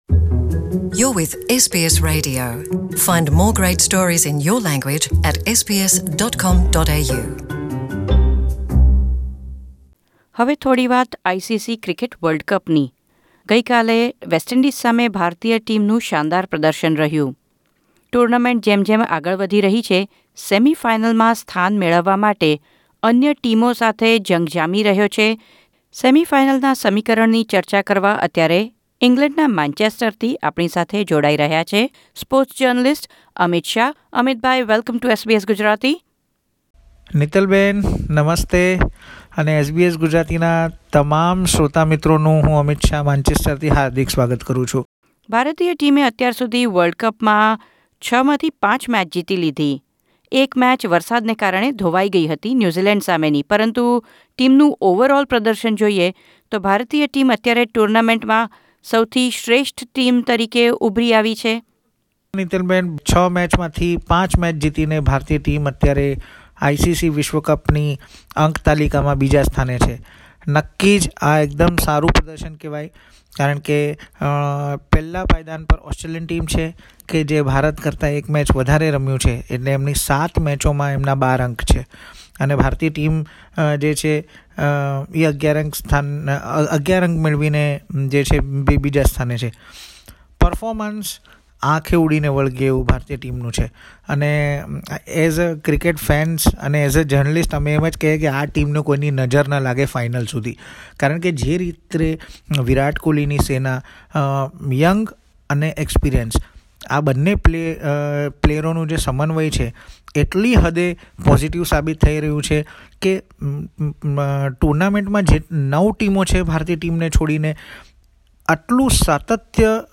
Australia became the first team to qualify for the 2019 ICC Cricket World Cup semi-finals, while India and New Zealand are just one win away to enter the last fours, which means host England and Pakistan will have to fight for the last spot. Sports Journalist